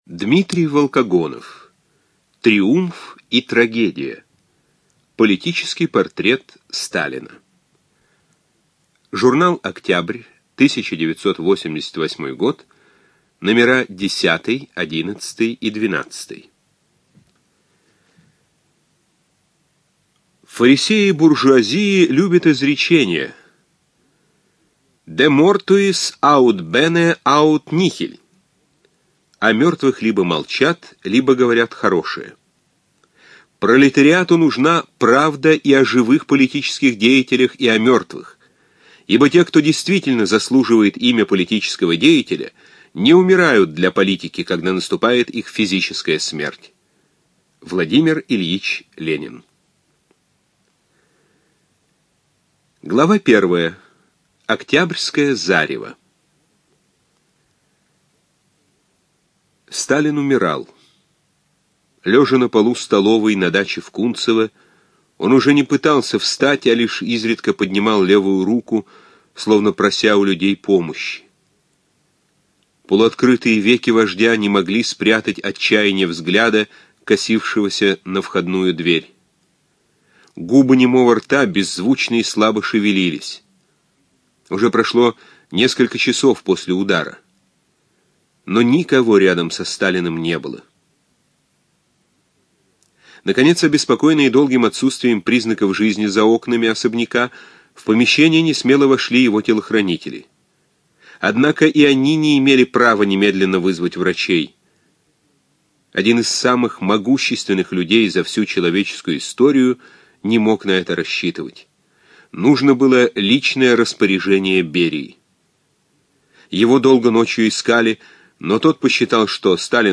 ЖанрБиографии и мемуары, Документальные фонограммы
Студия звукозаписиЛогосвос